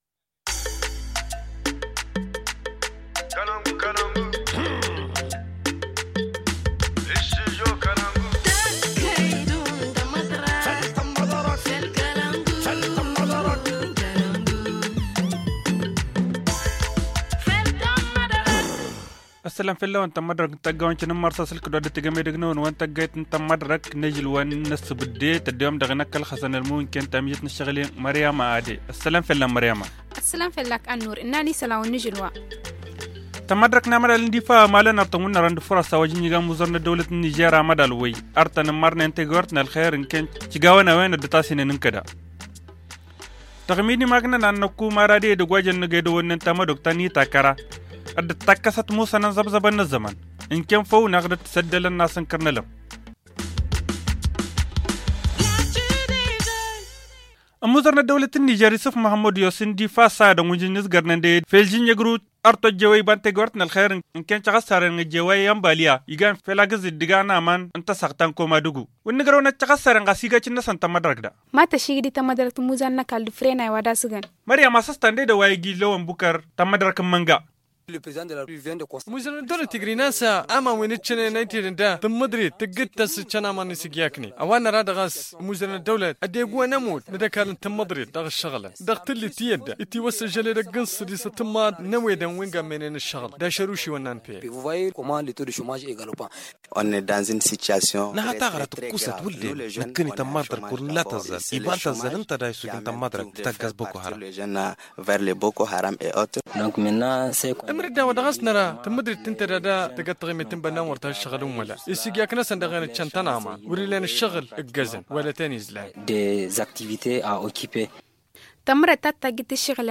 Le journal en tamasheq